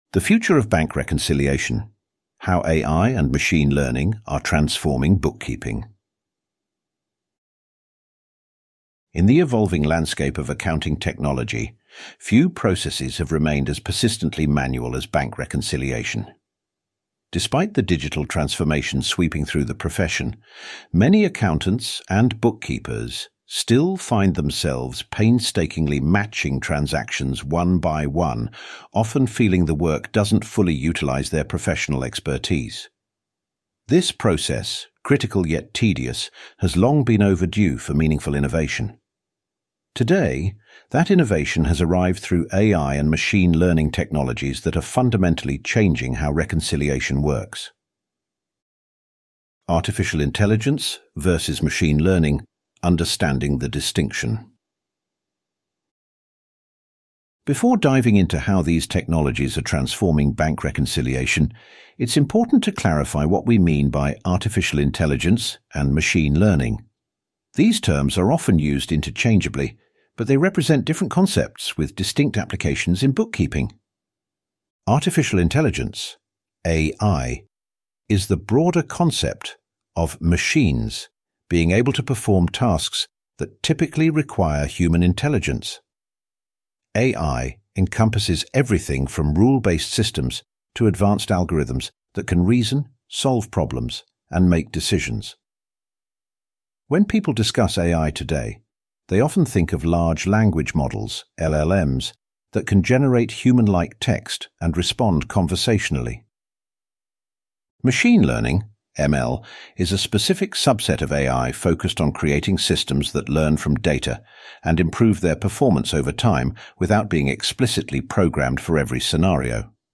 Audio version of The Future of Bank Reconciliation: How AI and Machine Learning are Transforming Bookkeeping
Listen to The Future of Bank Reconciliation: How AI and Machine Learning are Transforming Bookkeeping Narrated by Otto